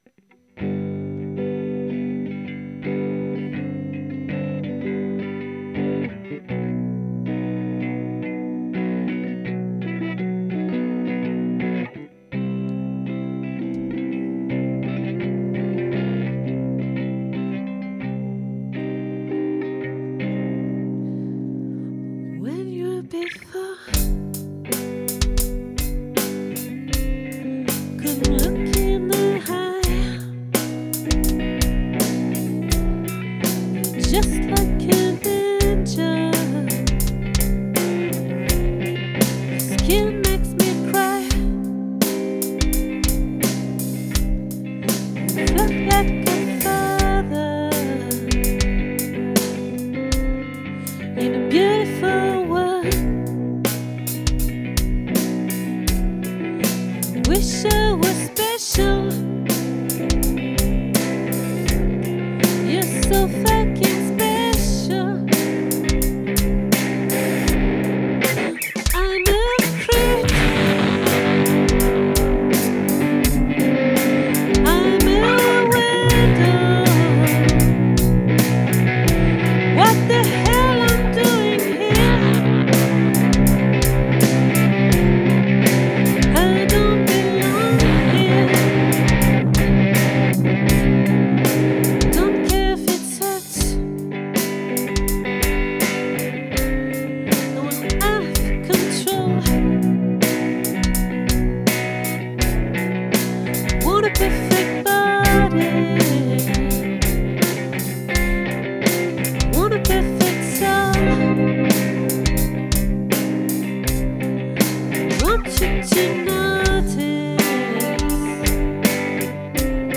🏠 Accueil Repetitions Records_2024_12_09